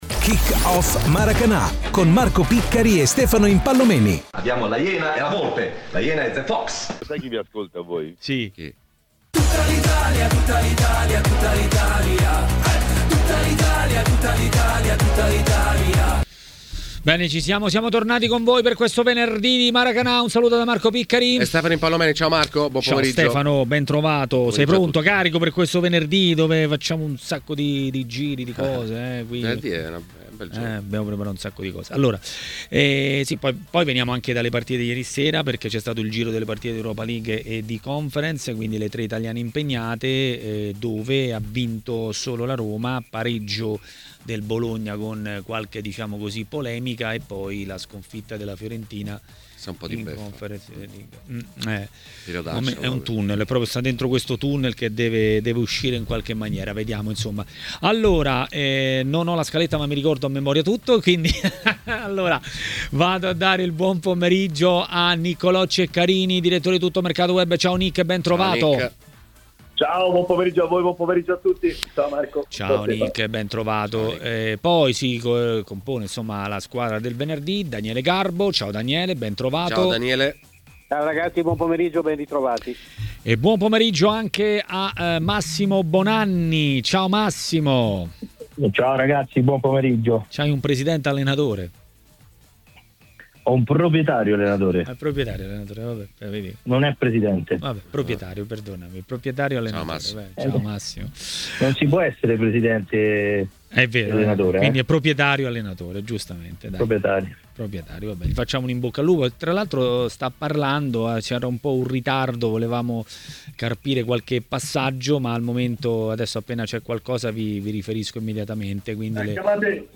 giornalista ed ex calciatore, ha parlato a Maracanà, nel pomeriggio di TMW Radio.